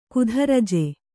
♪ kudharaje